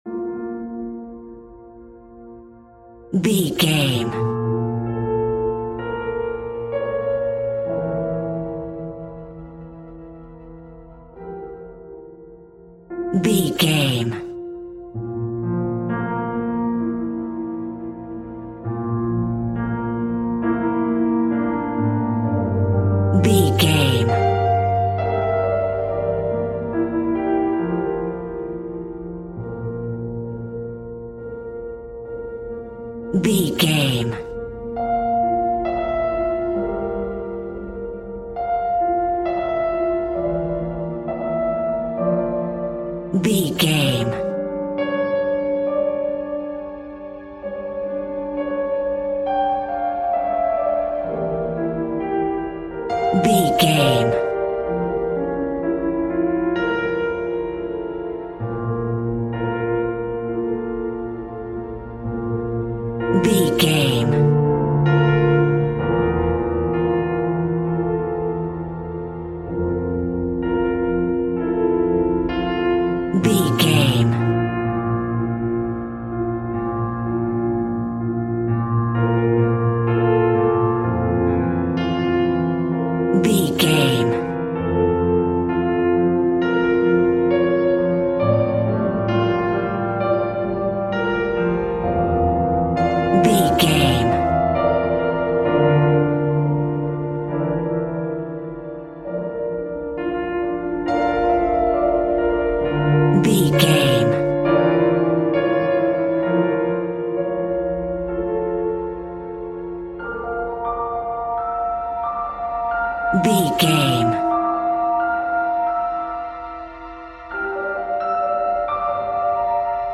Diminished
scary
tension
ominous
dark
haunting
eerie
horror
creepy
suspenseful